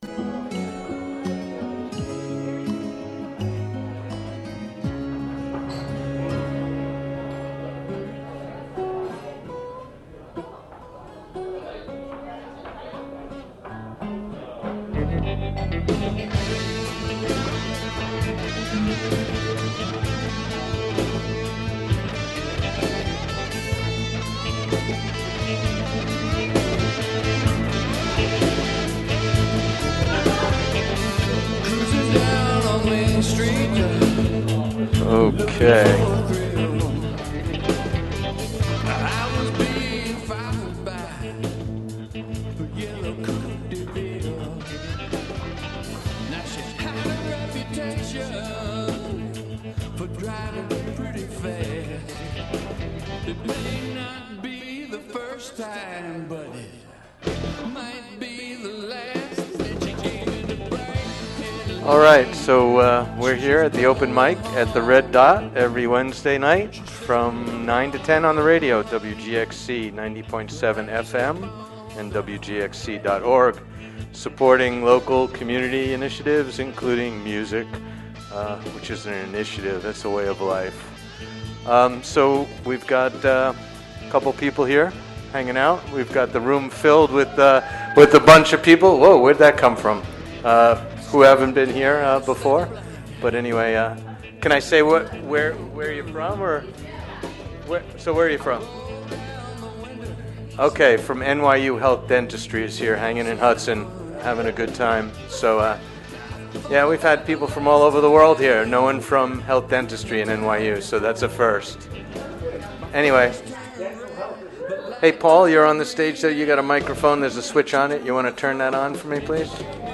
Live from The Red Dot Open Mic